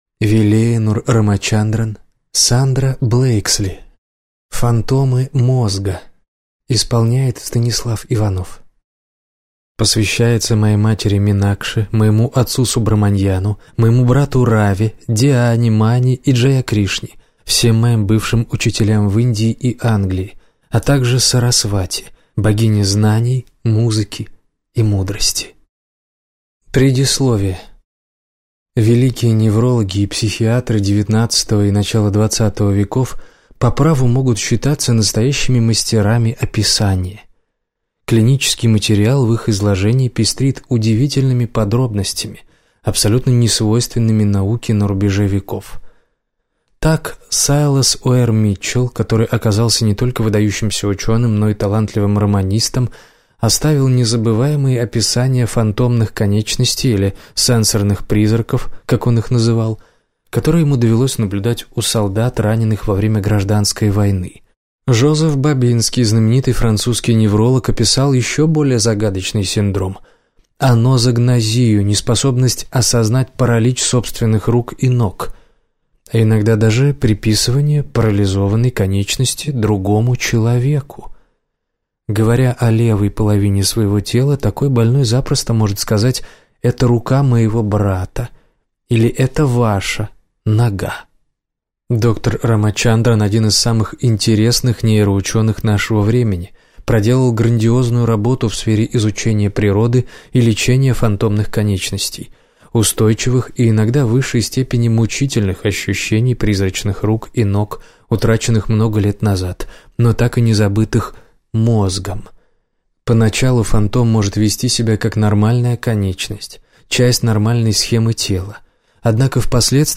Аудиокнига Фантомы мозга | Библиотека аудиокниг